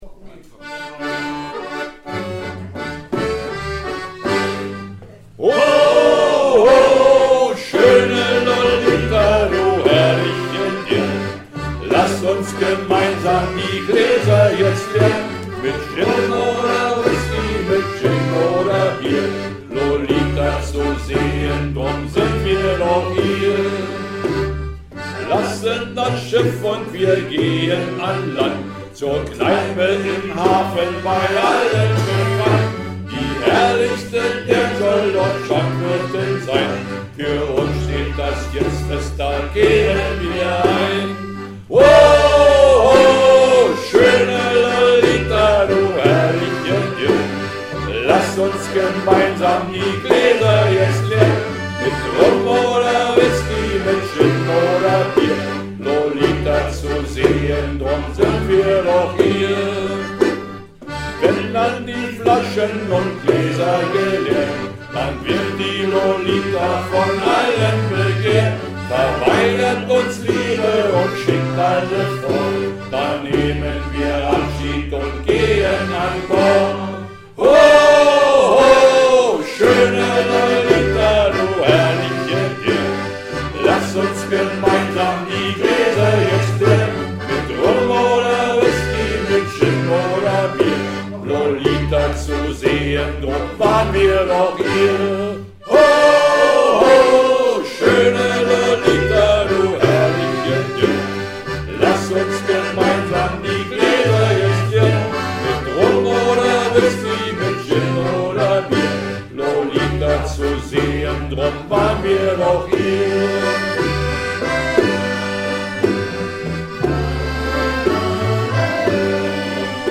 Shantys
Chor Chorgesang Jubiäum